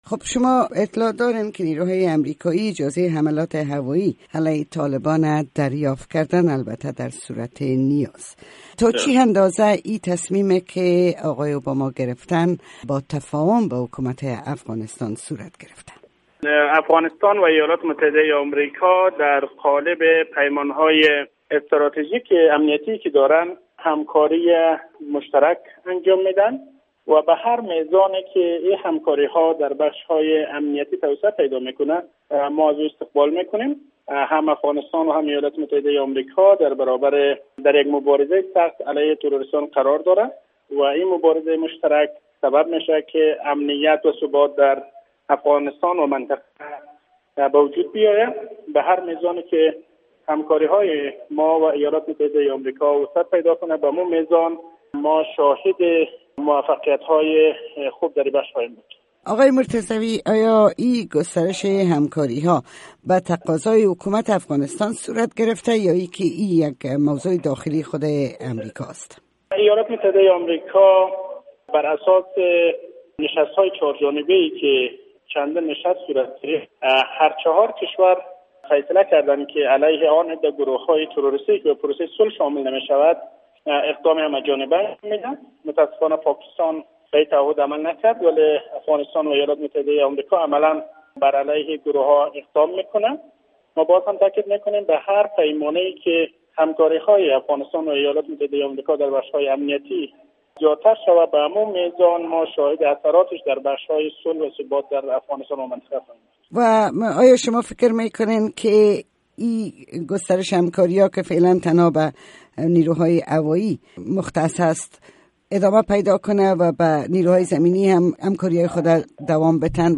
مصاحبه با شاه حسین مرتضوی، سخنگوی ریاست جمهوری افغانستان.